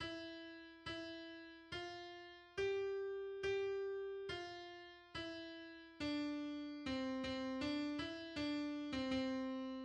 试听不同速度的欢乐颂。